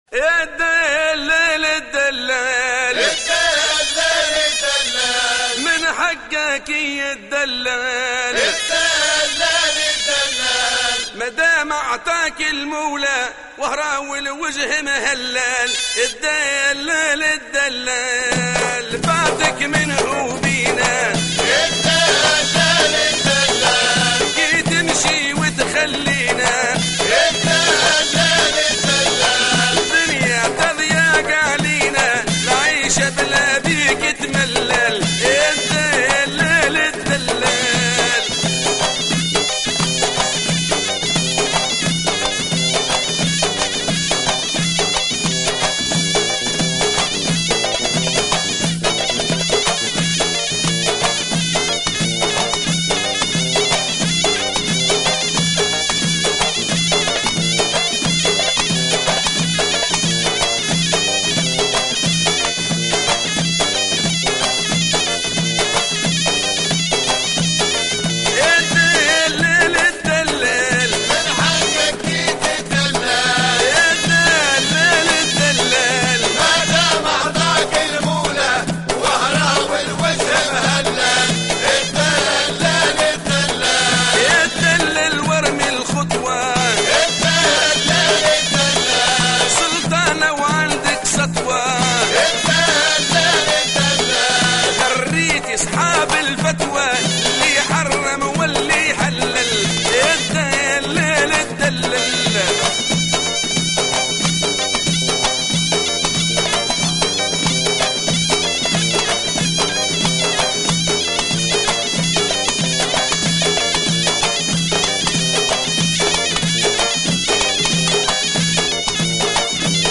Chanteurs
Bienvenue au site des amateurs de Mezoued Tunisien